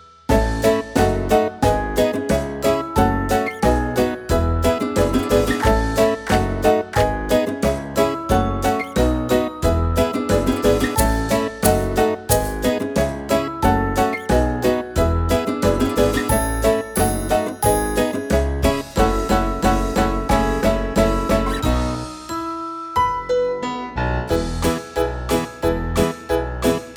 utwór w wersji instrumentalnej